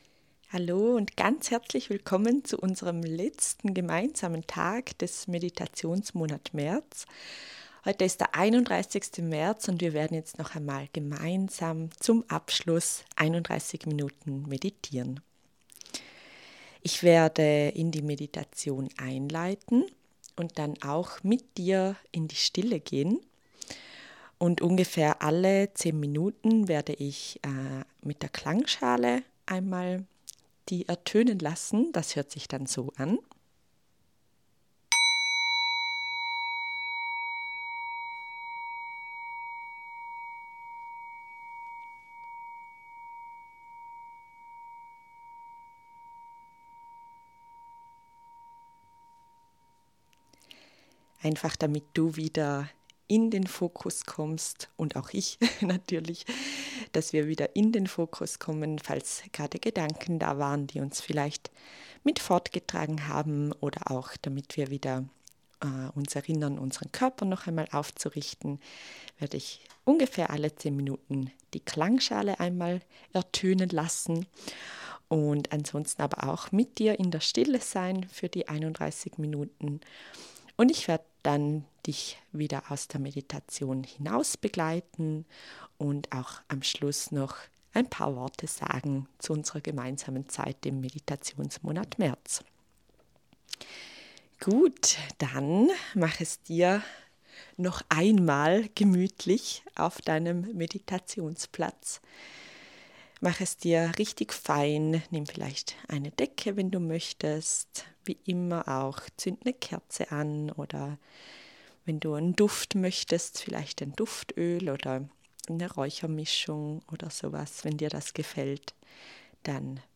Deine Meditation